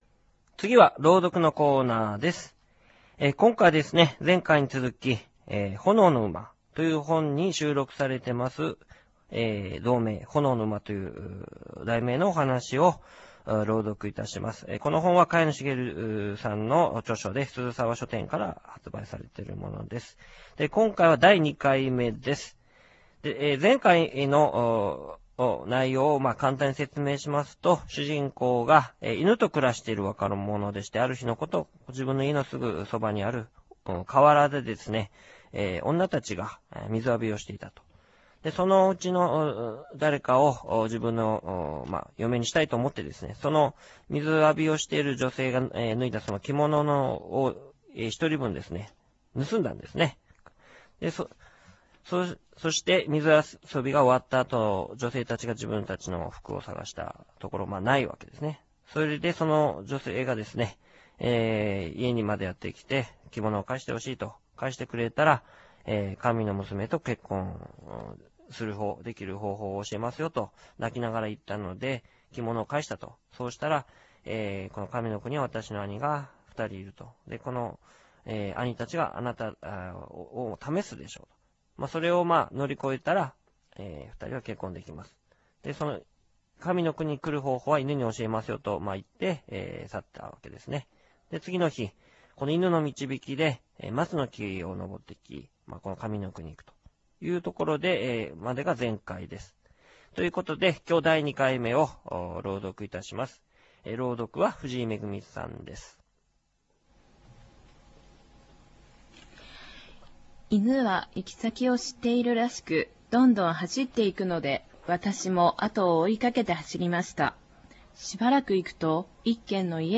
■yukar「ポンヤウンペ　マチヒ　アエイッカ」